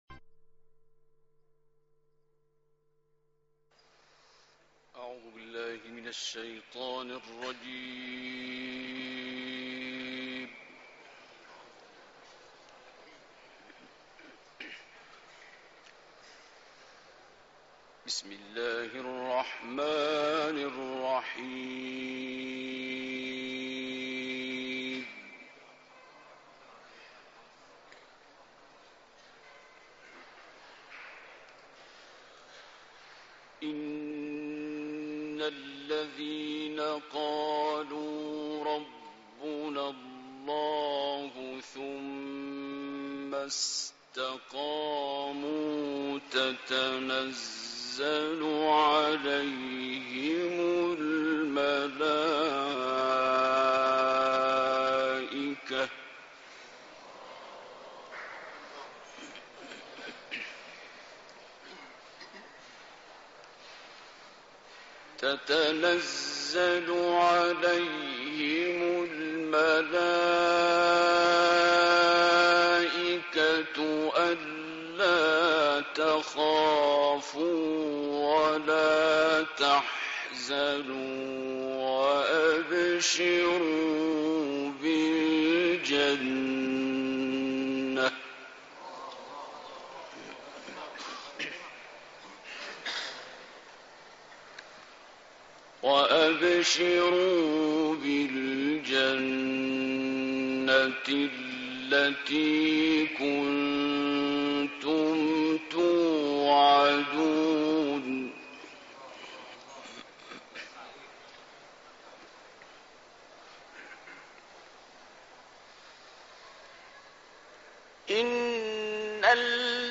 دانلود قرائت سوره فصلت آیات 30 تا 33 - استاد عبدالباسط محمد عبدالصمد